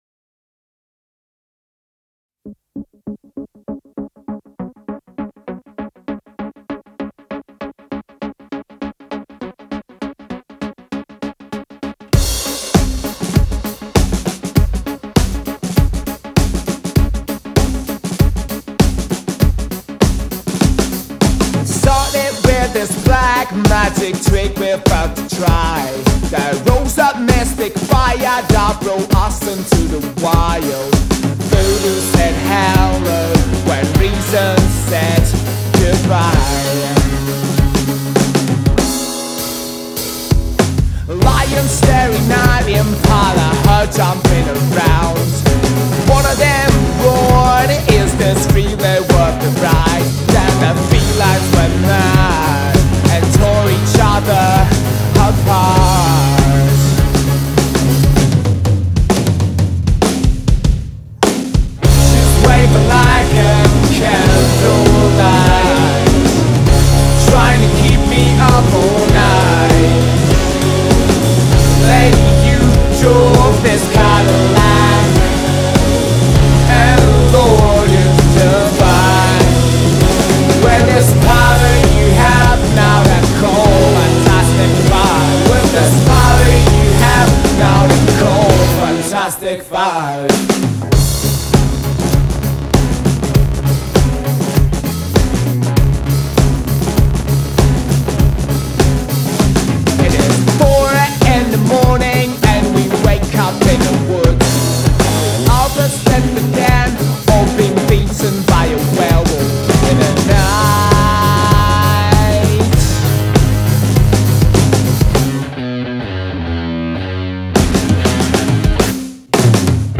c'est le Rock ‘n’ Roll moderne et percutant !